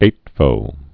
(ātvō)